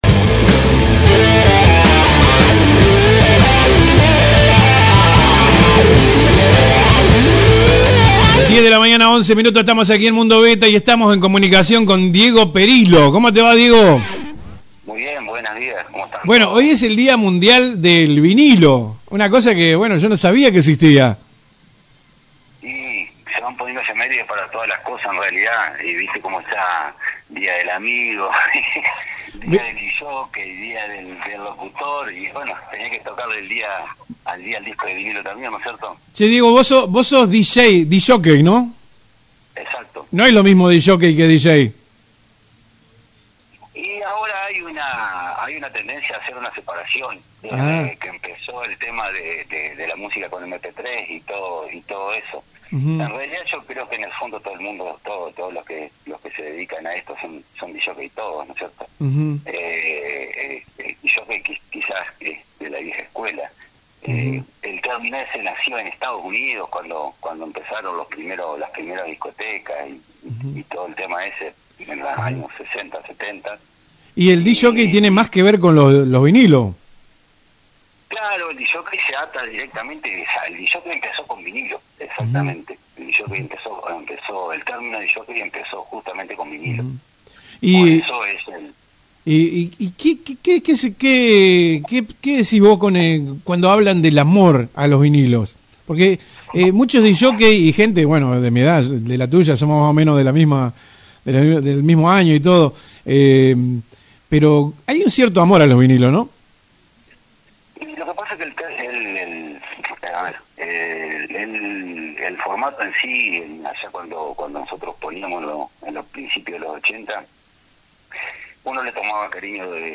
En una entrevista gustosamente brindada para Mundo Bettha detalló, minuciosamente los pasos y la evolución de los deseados discos de vinilo.